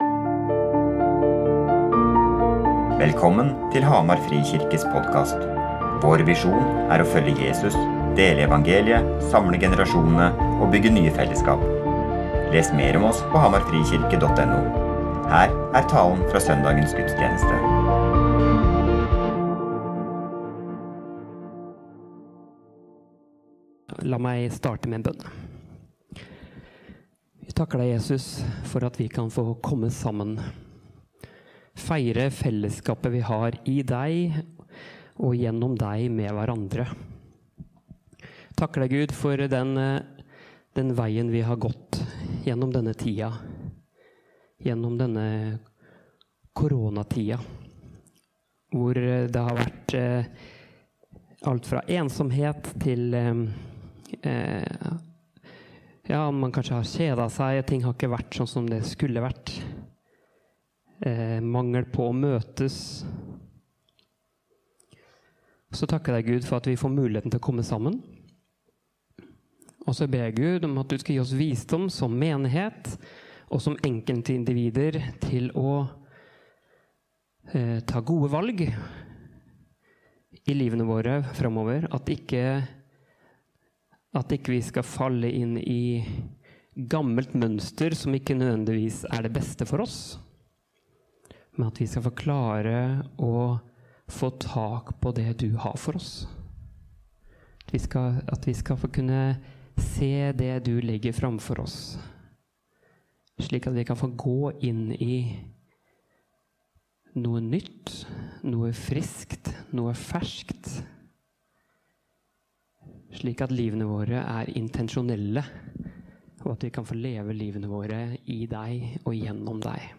Gudstjenesten